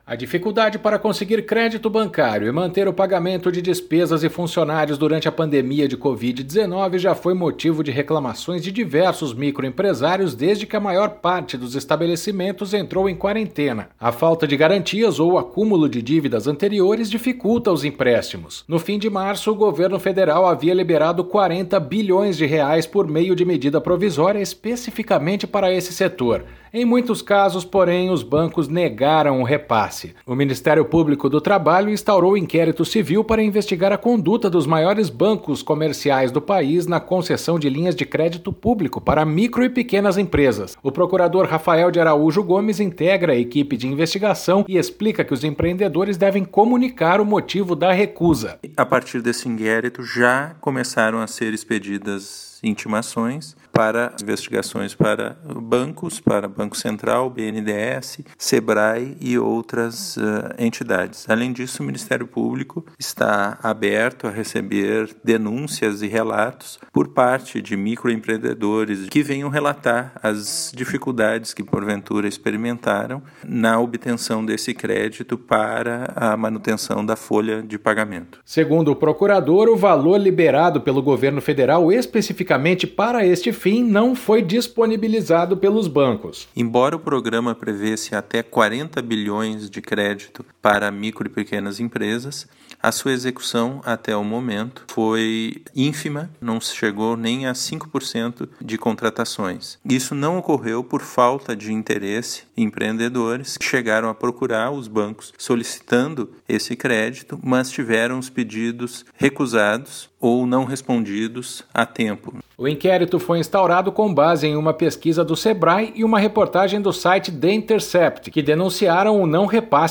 O procurador Rafael de Araújo Gomes integra a equipe de investigação e explica que os empreendedores devem comunicar o motivo da recusa.